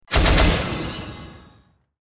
b_effect_rain.mp3